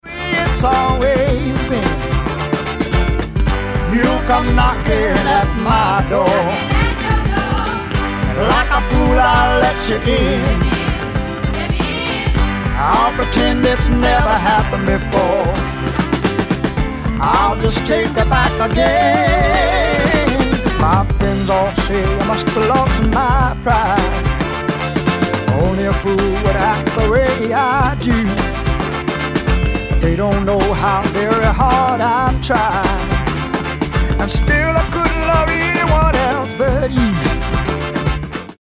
ギター、ヴォーカル
オルガン、ヴォーカル、フロント・マン
ドラムス